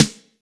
BEAT SD 04.WAV